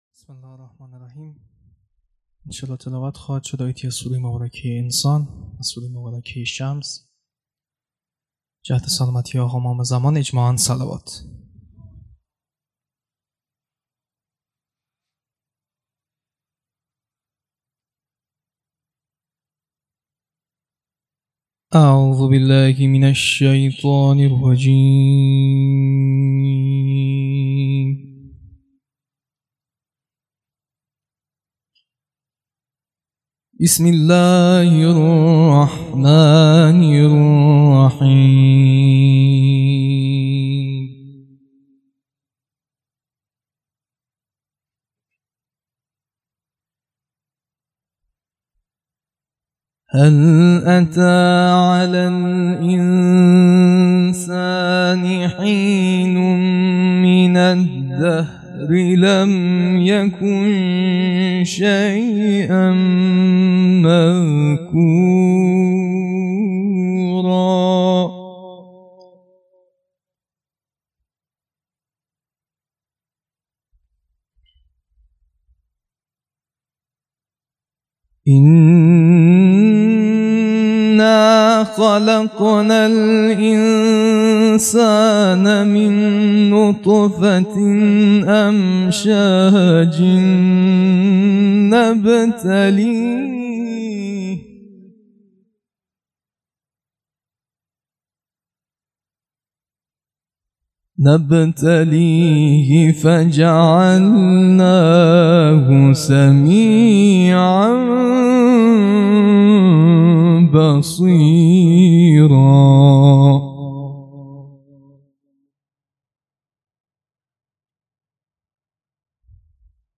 تلاوت مجلسی